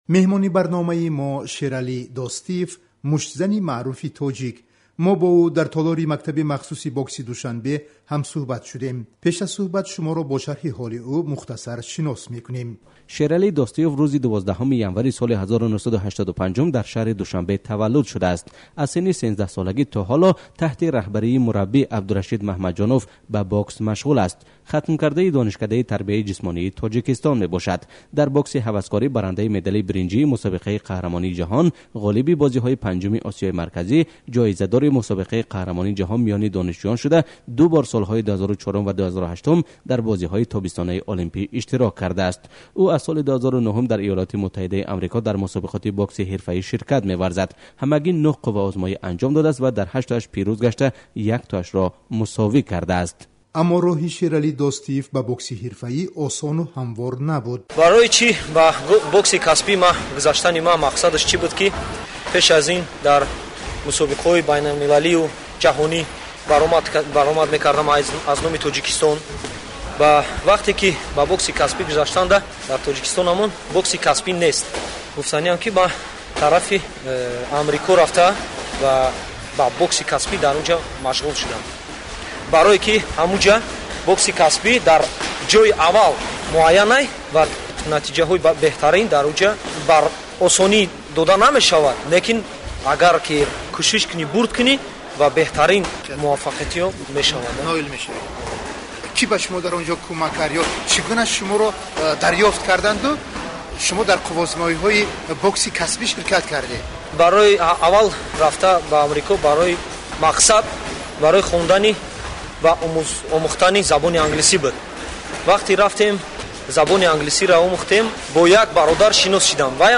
Сӯҳбати